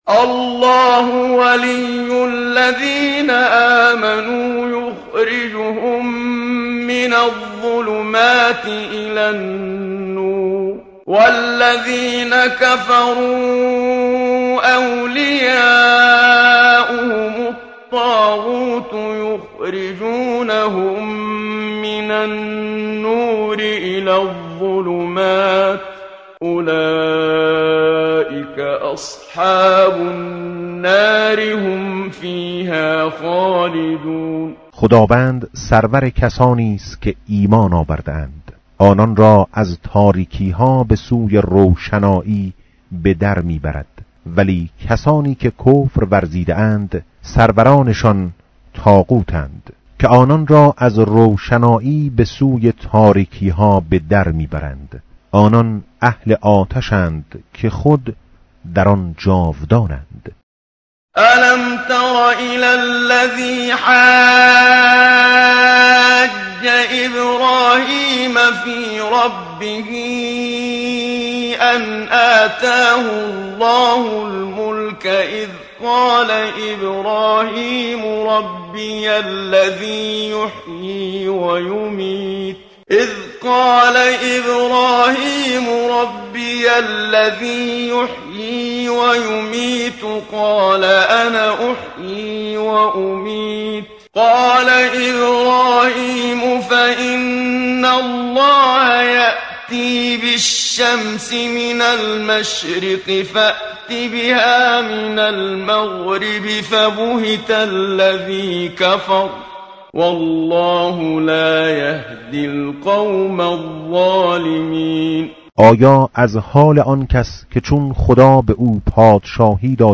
tartil_menshavi va tarjome_Page_043.mp3